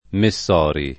[ me SS0 ri ]